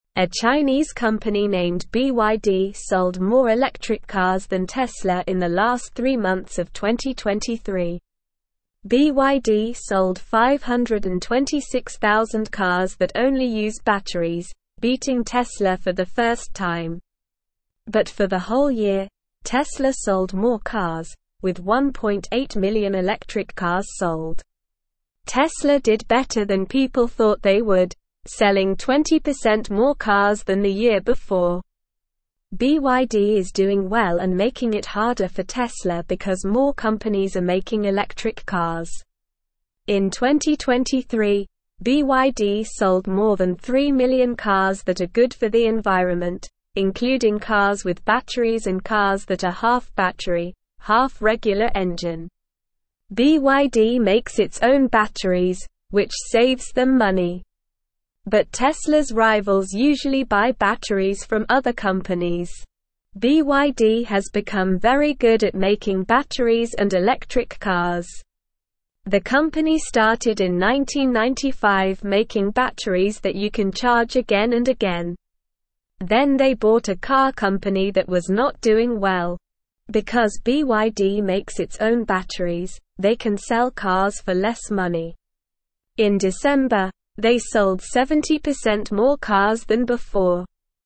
Slow
English-Newsroom-Lower-Intermediate-SLOW-Reading-BYD-sells-more-electric-cars-than-Tesla.mp3